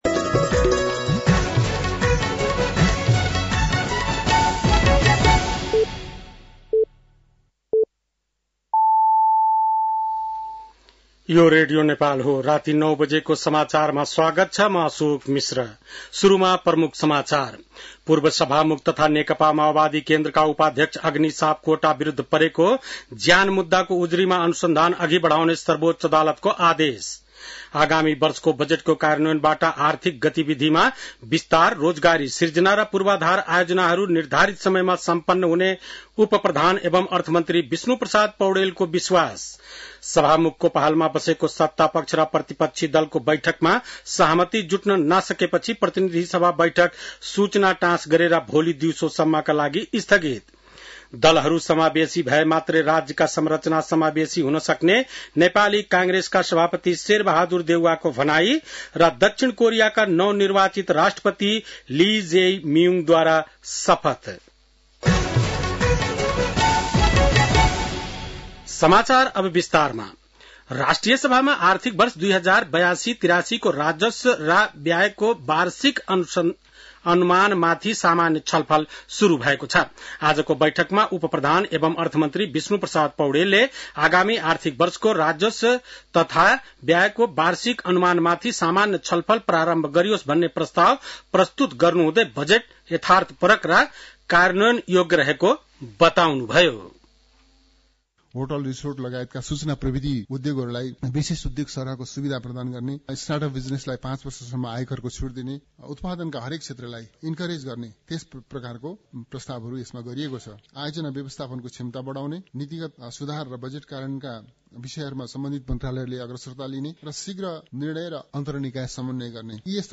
बेलुकी ९ बजेको नेपाली समाचार : २१ जेठ , २०८२